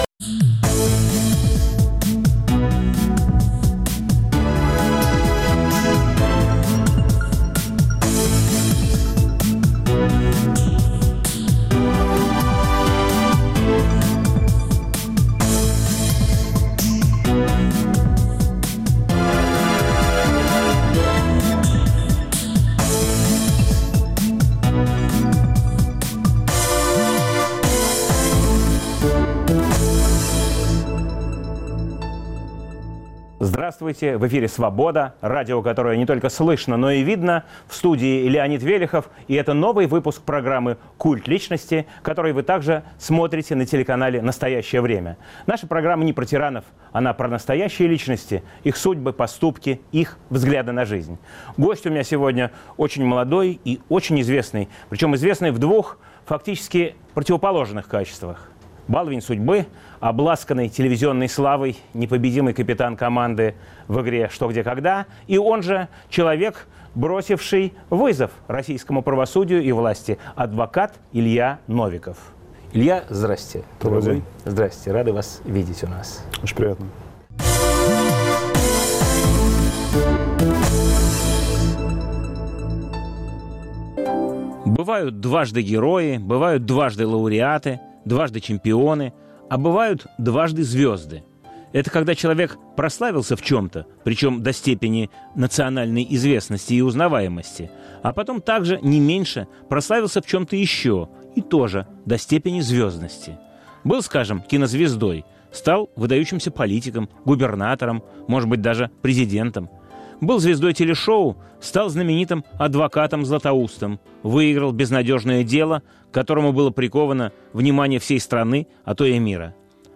Новый выпуск программы о настоящих личностях, их судьбах, поступках и взглядах на жизнь. В студии адвокат Илья Новиков.